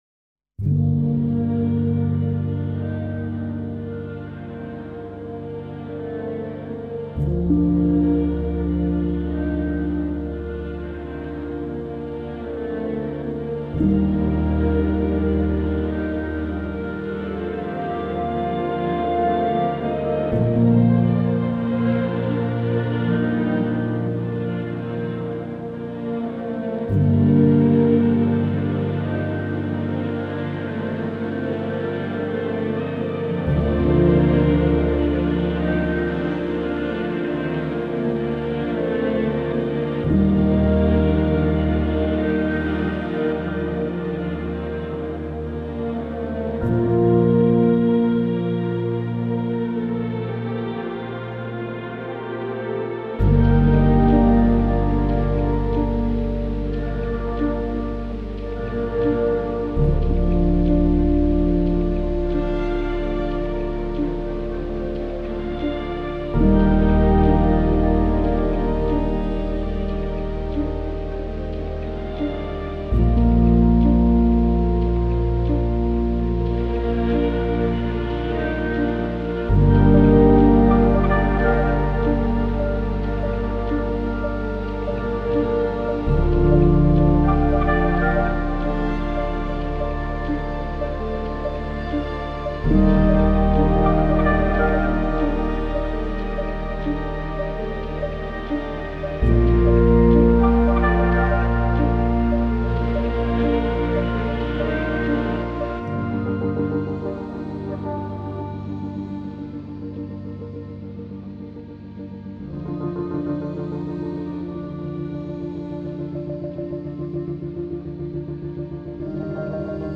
آرامش بخش
Classical Crossover
امبینت , پیانو